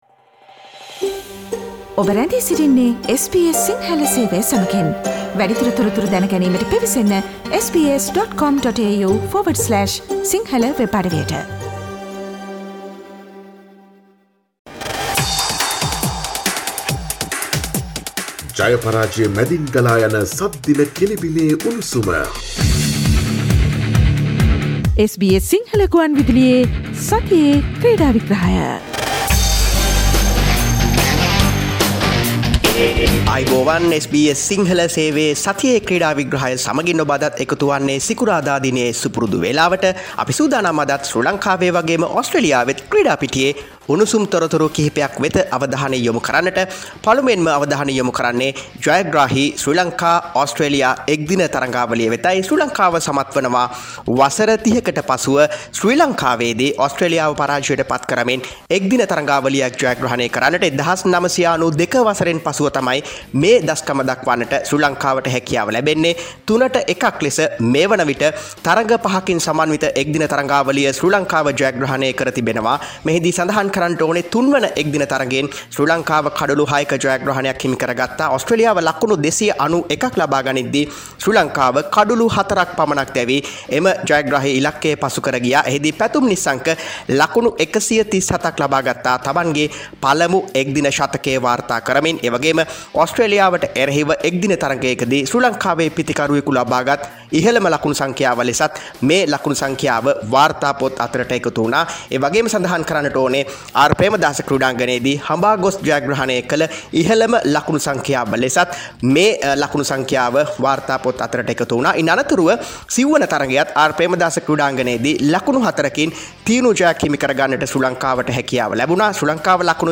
The Sri Lankan team's fitness is high, all tickets for the 5th ODI have been sold out: Weekly Sports wrap